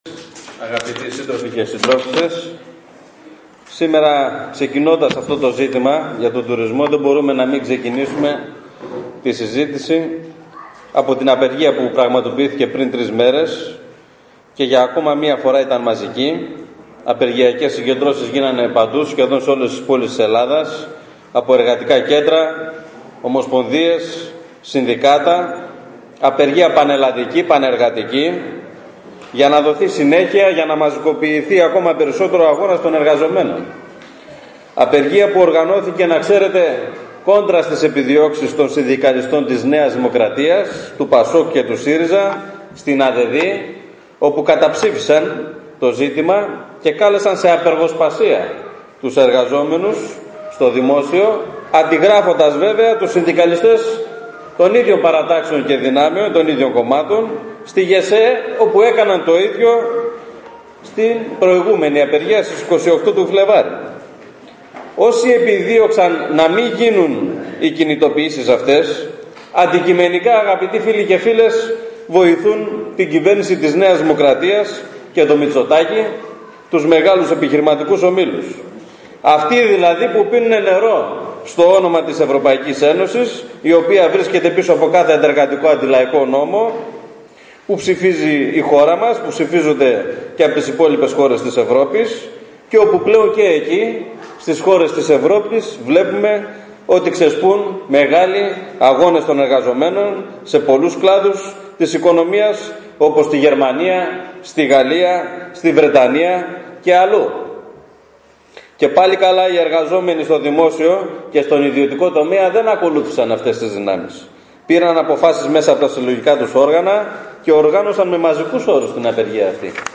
Προεκλογική ομιλία για τις Ευρωεκλογές του ΚΚΕ στην αίθουσα του Εργατικού Κέντρου